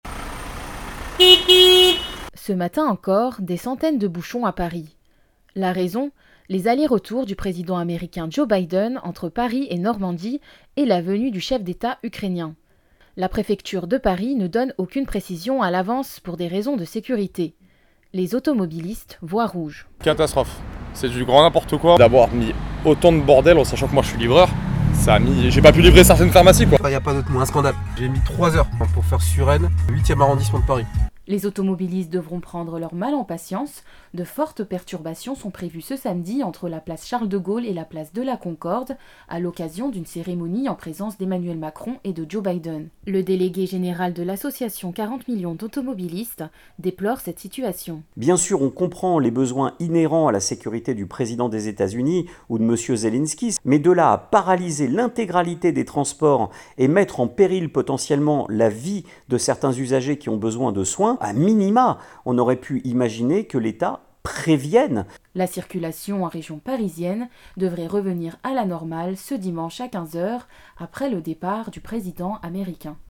Les perturbations de circulations continuent aujourd’hui avec les allers retours du président américain Joe Biden et la venue ce matin du chef de l’Etat Ukrainien. La tension est palpable chez les automobiliste franciliens. Un reportage